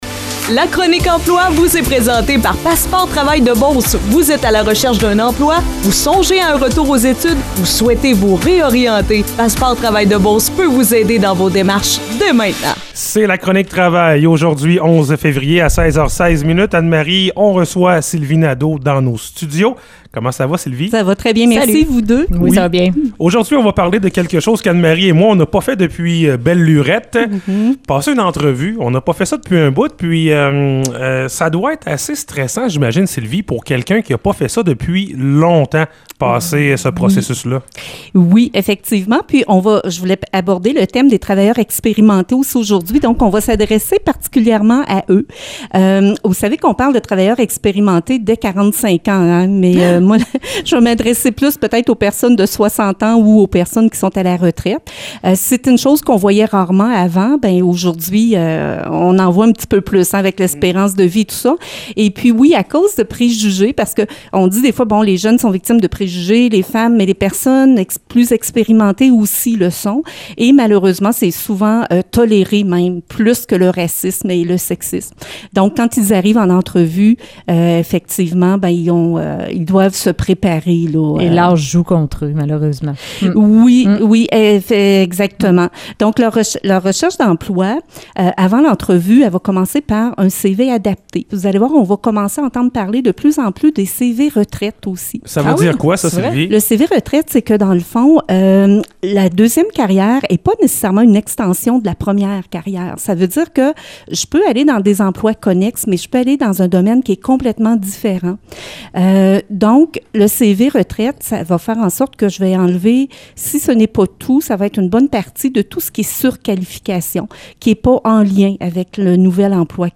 Vous avez manqué notre chronique de l'emploi sur les ondes de Mix 99,7 ou vous désirez tout simplement la réécouter, alors cliquez sur le lien.